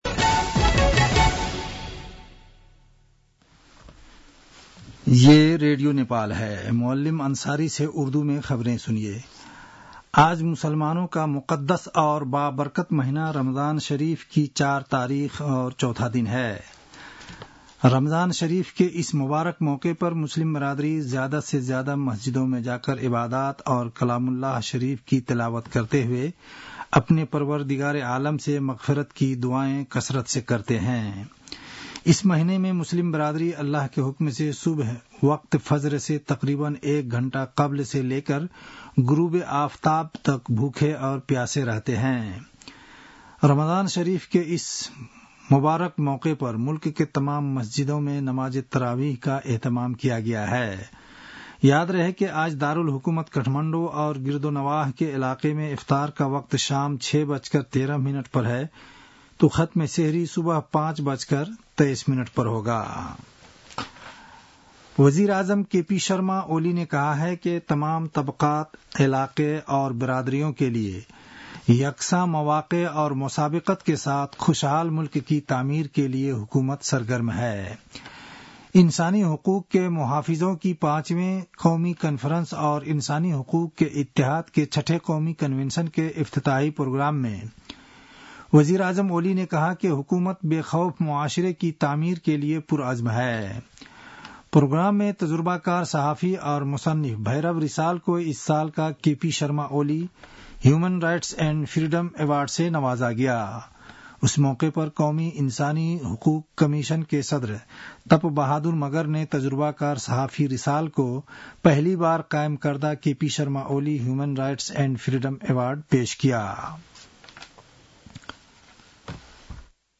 An online outlet of Nepal's national radio broadcaster
उर्दु भाषामा समाचार : २२ फागुन , २०८१
urdu-news-11-21.mp3